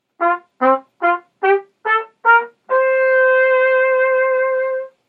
I want to see if I can print a functional copy of my trumpet mouthpiece, a Monette B2.